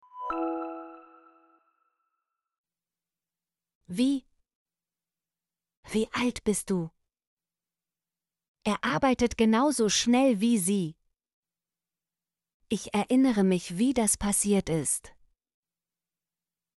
wie - Example Sentences & Pronunciation, German Frequency List